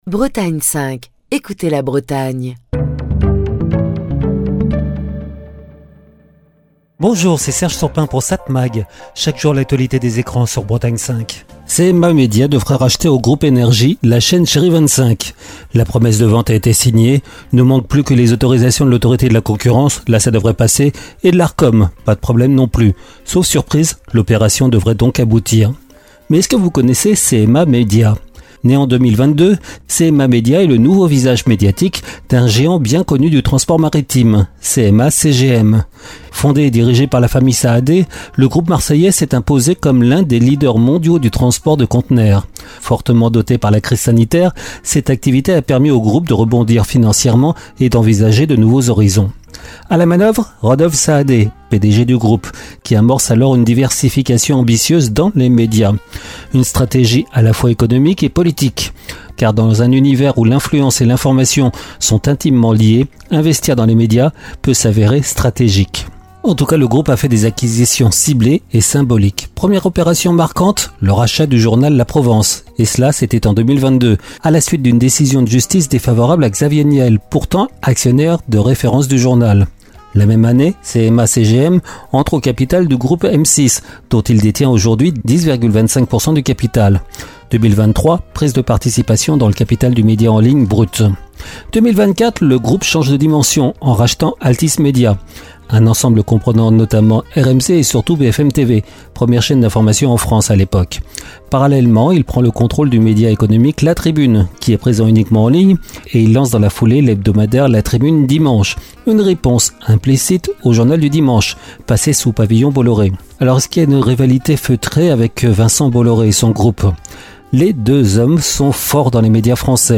Chronique du 13 mai 2025.